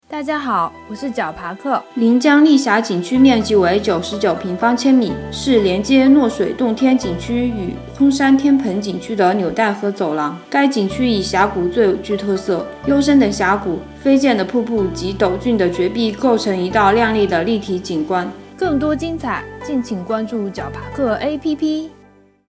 临江丽峡景区----- fin 解说词: 临江丽峡景区面积为99平方千米，是连接诺水洞天景区与空山天盆景区的纽带和走廊。